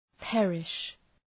{‘perıʃ}
perish.mp3